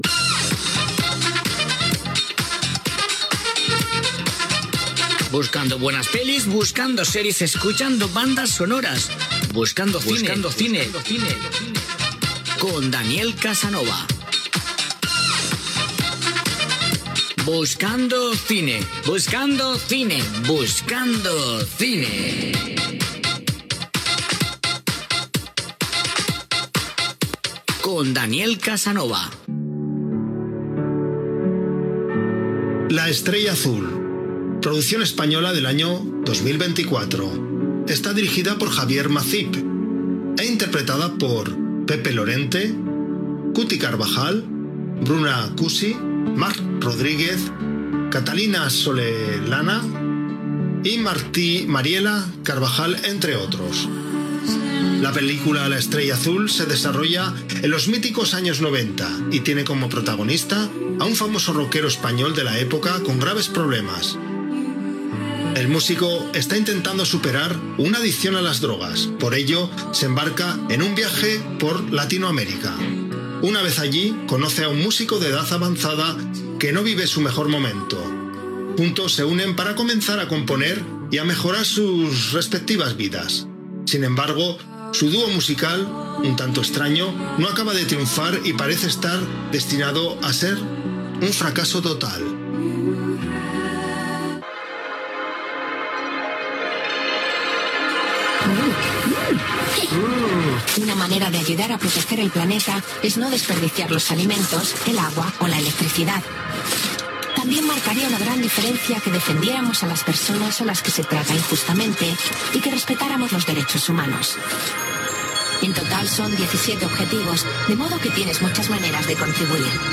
Recomanació de pel·lícules amb els seus arguments, indicatiu del programa, indicatiu de la ràdio, inici del programa "Frecuencia 80"